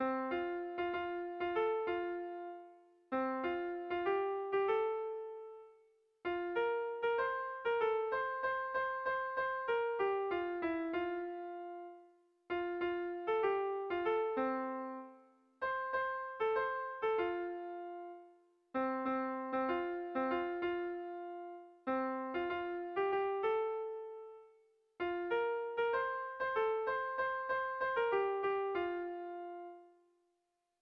Kontakizunezkoa
Ibarra < Tolosaldea < Gipuzkoa < Euskal Herria
Hamarreko txikia (hg) / Bost puntuko txikia (ip)
ABDAB